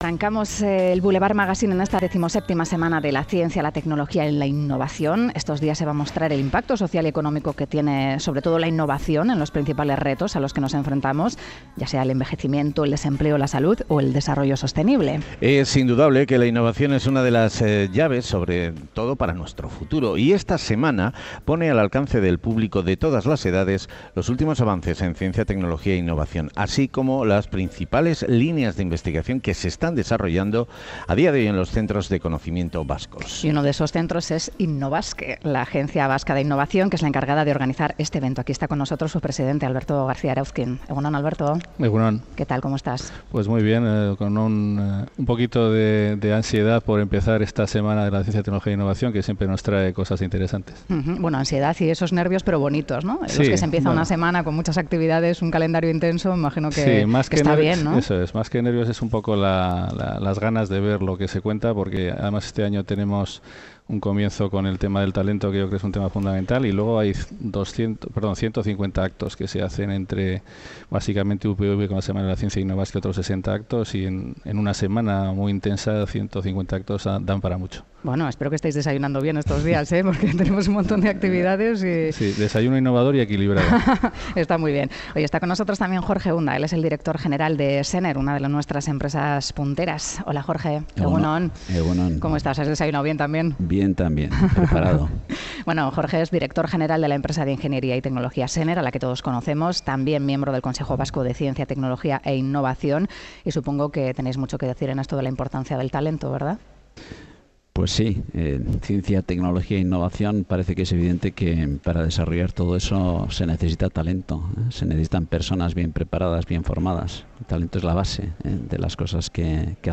Audio: Programa especial de 'Boulevard' desde el Palacio Euskalduna con motivo de la XVII Semana de la Ciencia, la Tecnología y la Innovación. Entrevistas en directo.